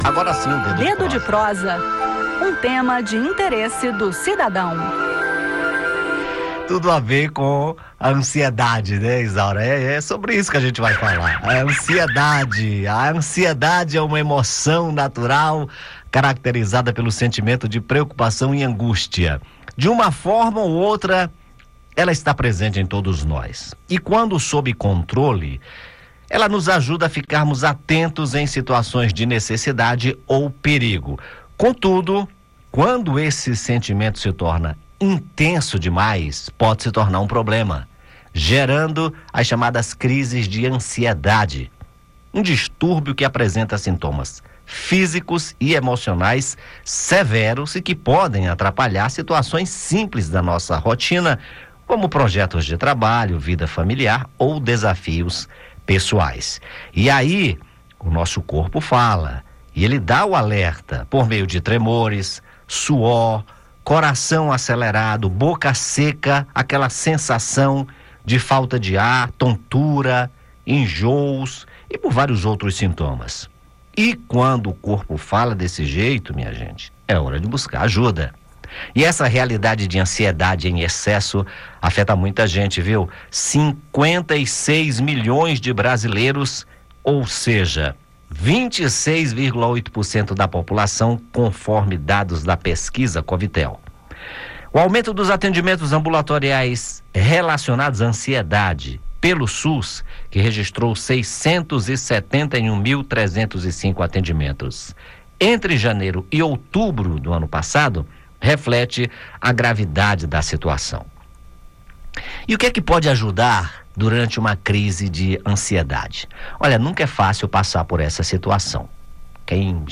Mas em alguns casos a ansiedade pode se tornar um problema, gerando crises com sintomas físicos e emocionais severos. No bate-papo, ouça dicas para lidar com o problema e saiba quando buscar ajuda profissional.